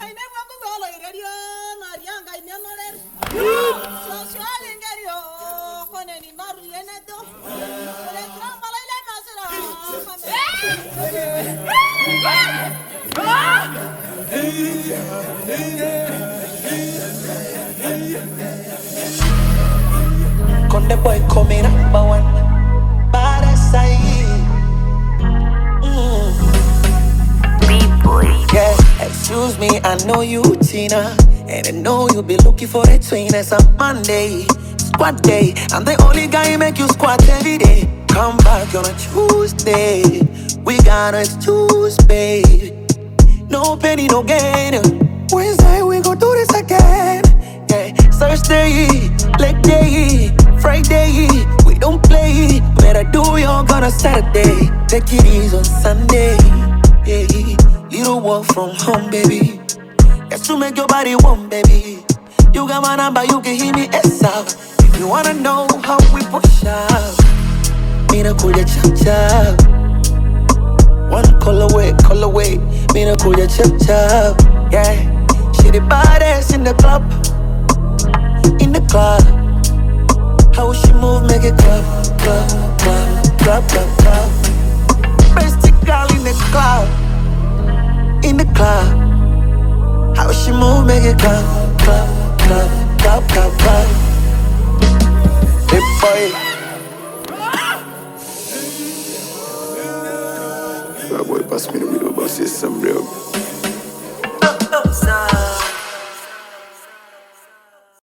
high-energy tracks